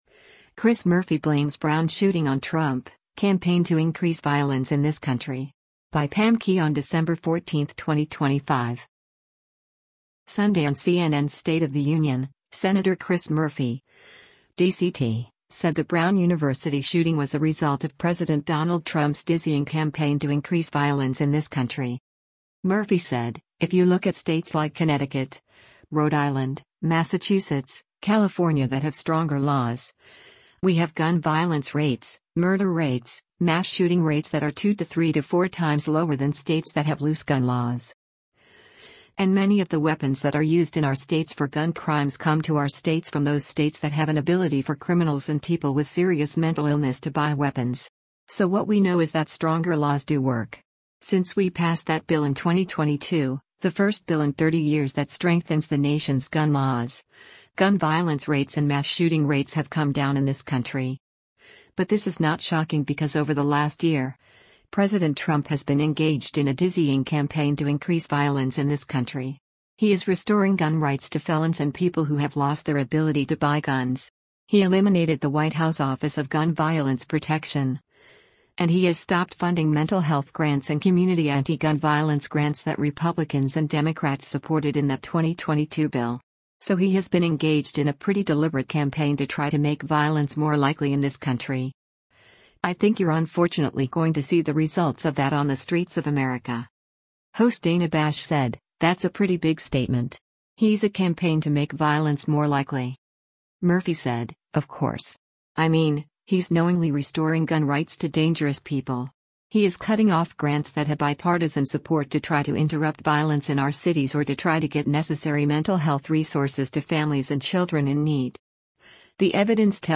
Sunday on CNN’s “State of the Union,” Sen. Chris Murphy (D-CT) said the Brown University shooting was a result of President Donald Trump’s “dizzying campaign to increase violence in this country.”